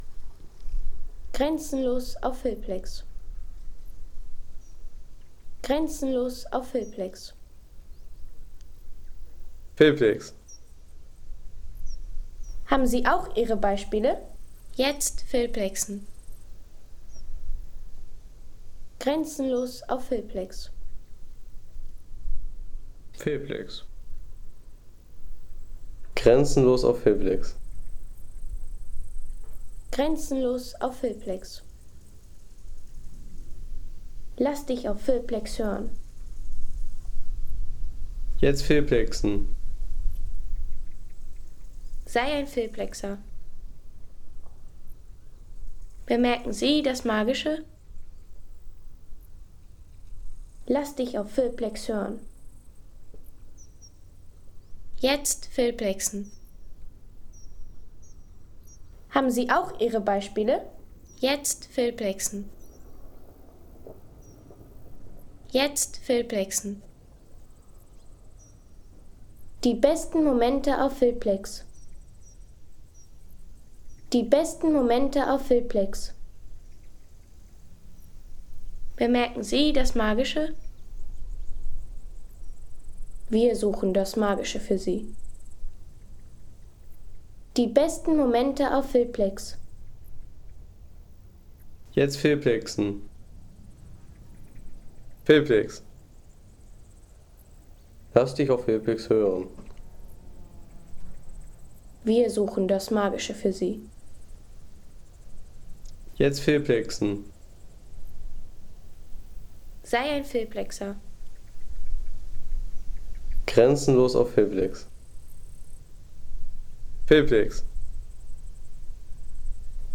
Schöckl-Plateau Naturerlebnis Sound | Feelplex
Ein Bergsound, bei dem der Alltag unten bleiben muss
Natürliche Bergplateau-Atmosphäre vom Schöckl auf 1342 m mit Wind in Bäumen, Vogelstimmen und ruhiger Wiesenlandschaft.
Natürliche Atmosphäre vom Schöckl-Plateau mit Windrauschen, Vogelstimmen und weiter Wiesenruhe.